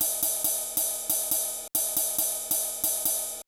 JAZZ RIDE -R.wav